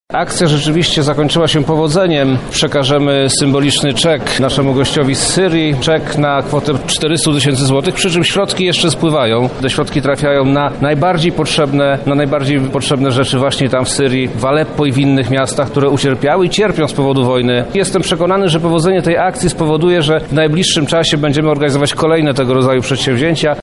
Podsumowanie projektu odbyło się na Zamku Lubelskim z udziałem wojewody lubelskiego Przemysława Czarnka,
Projekt podsumowuje wojewoda lubelski Przemysław Czarnek: